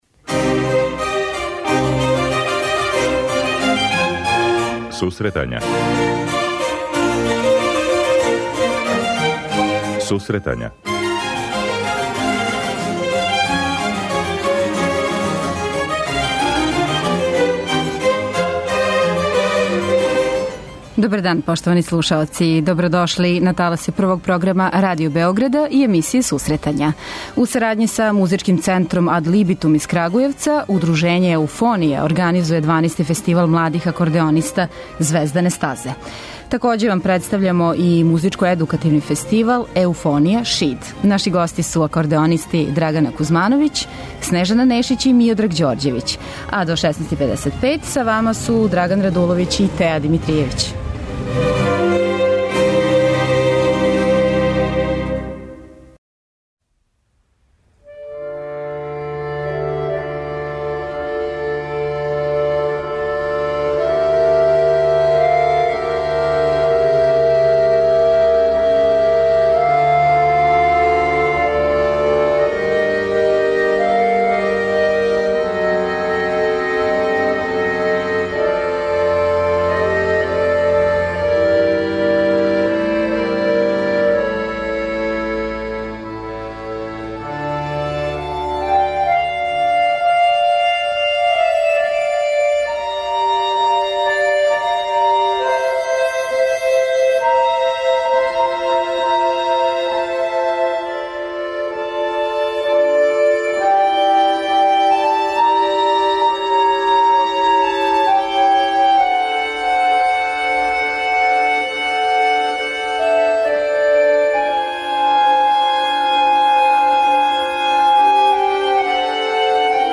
преузми : 25.81 MB Сусретања Autor: Музичка редакција Емисија за оне који воле уметничку музику.